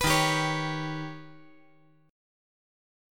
D#+7 Chord
Listen to D#+7 strummed